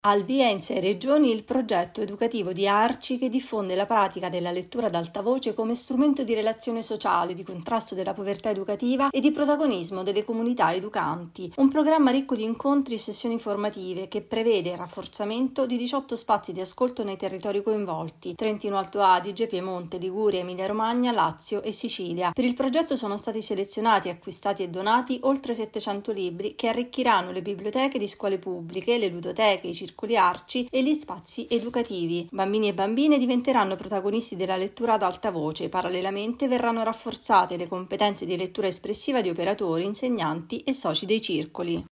Con i Bambini sostiene le aree colpite dall’alluvione dello scorso anno in Emilia-Romagna, Toscana e Marche. Il servizio